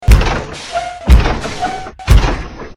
vrac_talking.ogg